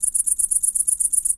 INSECT_Crickets_Segment_03_mono.wav